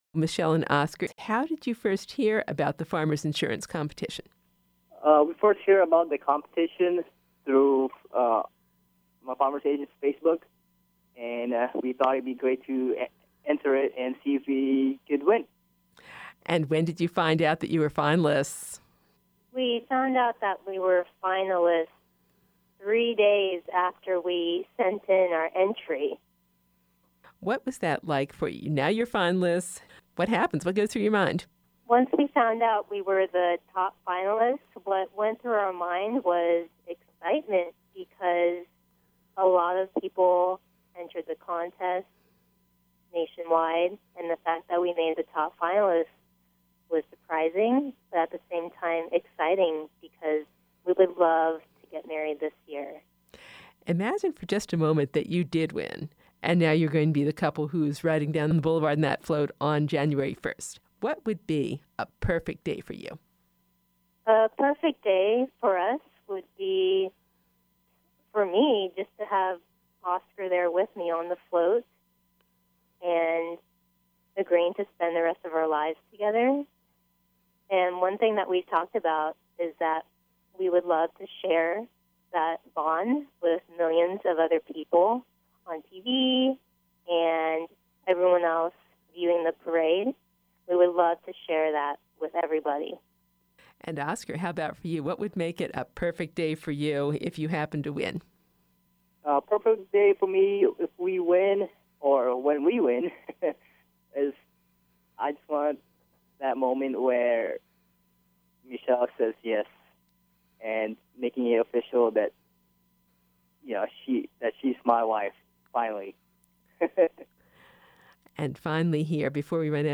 “Love Float” Interview, Part 2